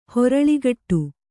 ♪ horaḷigaṭṭu